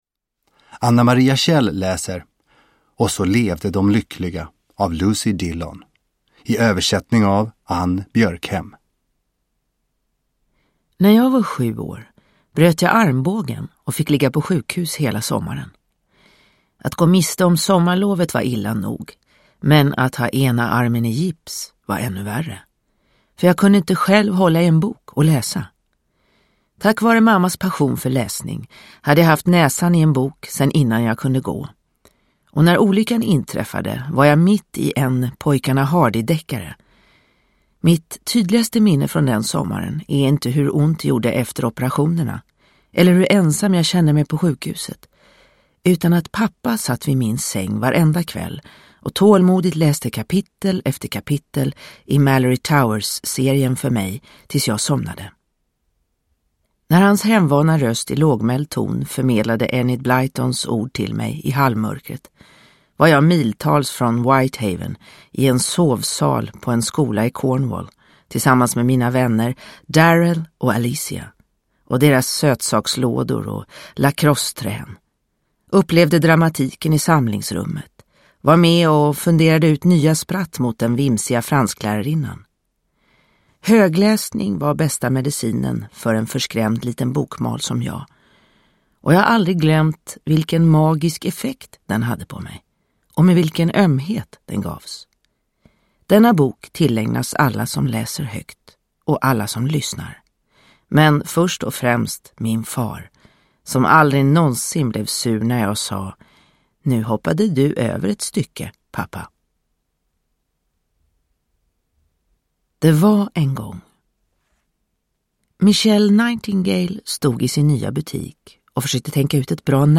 Och så levde de lyckliga – Ljudbok – Laddas ner